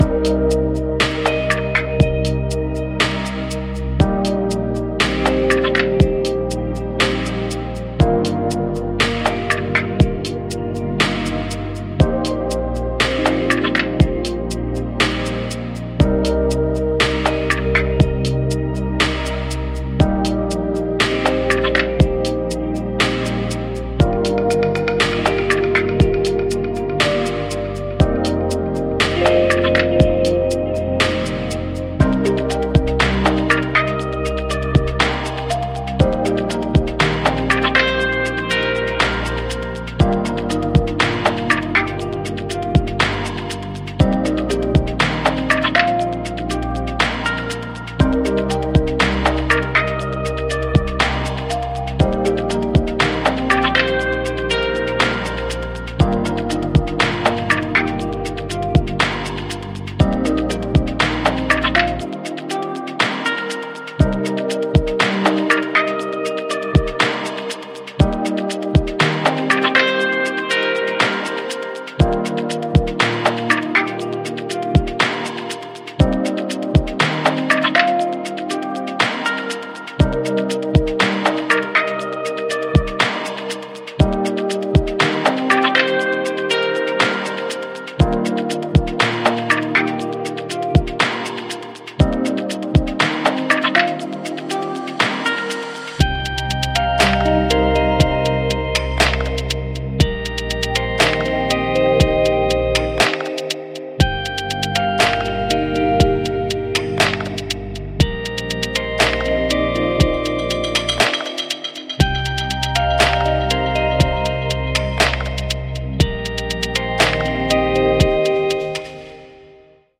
High-Quality Music Generated by AI